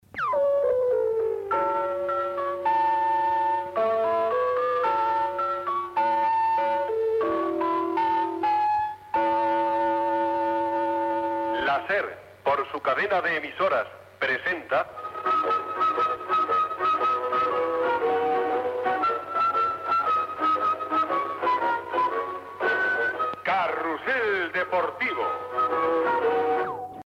Sintonia de la cadena SER i cartea del programa